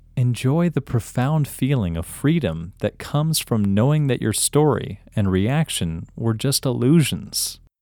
OUT – English Male 32